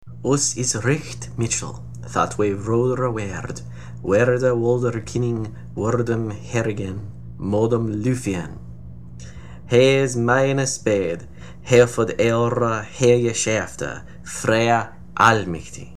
The recording comes from: Anglo-Saxon Aloud – A daily reading of the entire Anglo-Saxon Poetic Records, which includes all poems written in Old English.